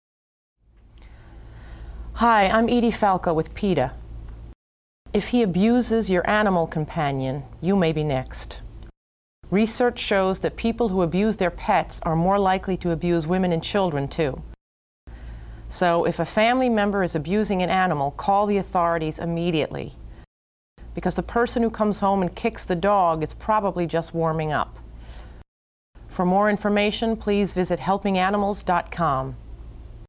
Instructions for Downloading This Radio PSA Audio File